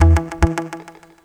RIFFGTR 05-L.wav